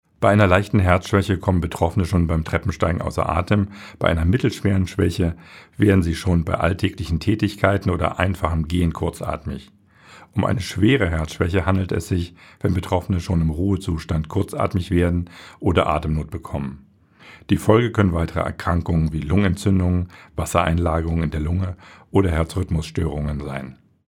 O-Töne